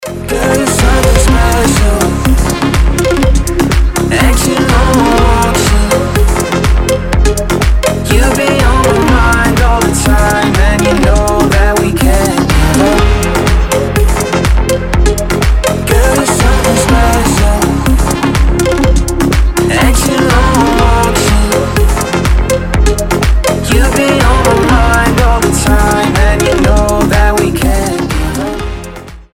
мужской вокал
dance
Electronic
EDM
приятные
house